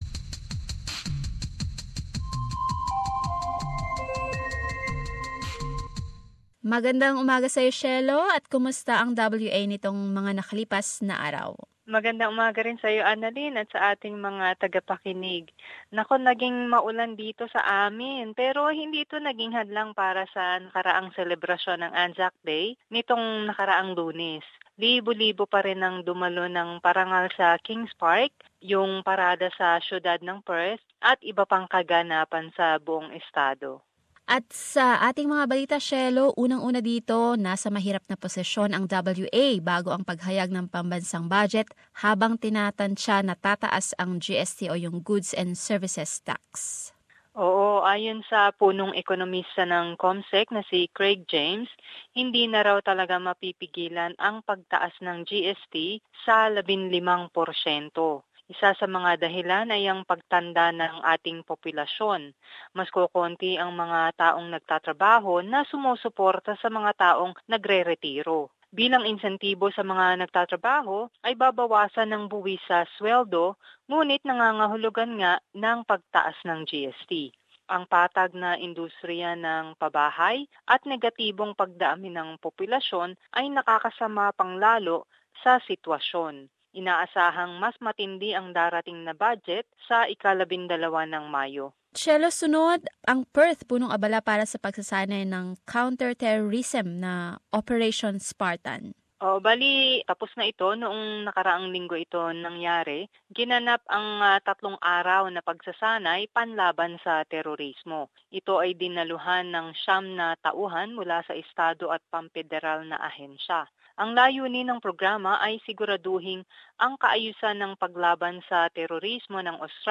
Perth Report.